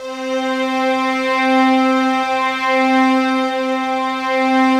Index of /90_sSampleCDs/Optical Media International - Sonic Images Library/SI1_Swell String/SI1_Octaves